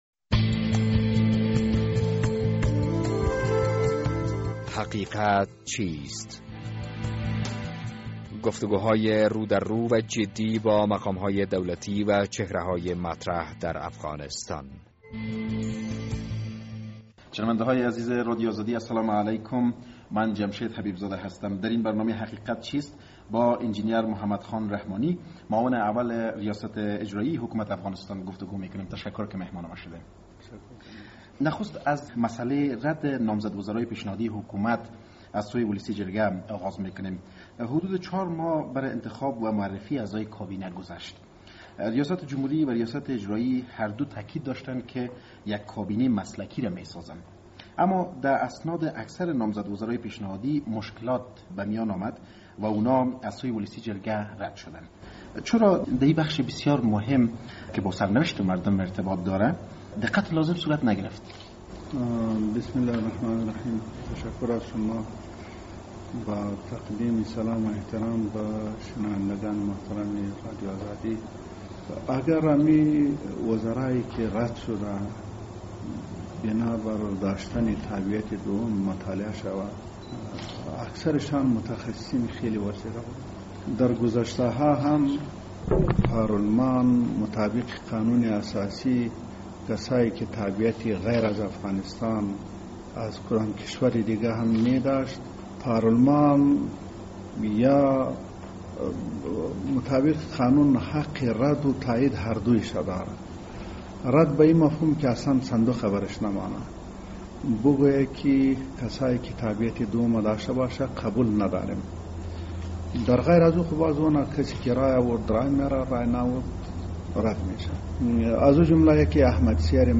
در این برنامه حقیقت چیست با محمد خان رحمانی معاون اول ریاست اجرایی حکومت وحدت ملی افغانستان گفتگو کرده ایم.